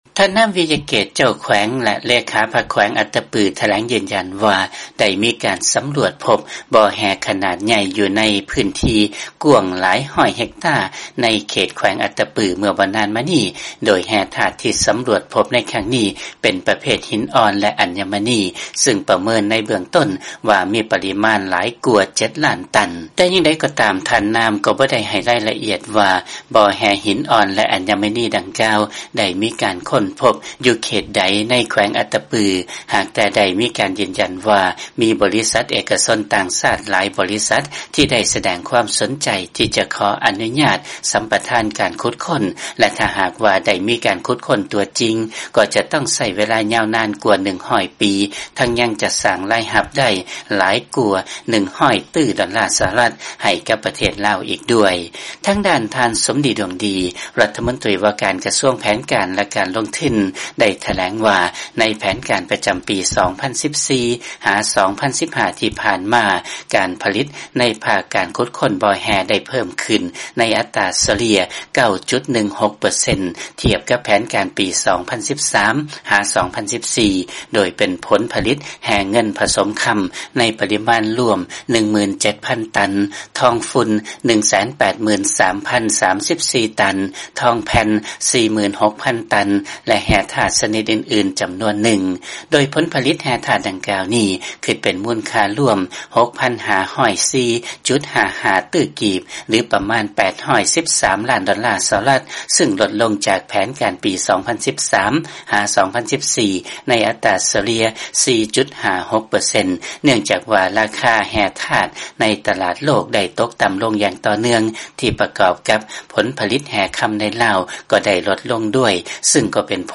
ເຊີນຟັງລາຍງານ ເຈົ້າແຂວງ ອັດຕະປື ຢືນຢັນວ່າ ໄດ້ມີການສຳຫຼວດພົບບໍ່ແຮ່ ເປັນປະເພດຫິນອ່ອນ ແລະ ອັນຍະມະນີ ຢູ່ໃນແຂວງ.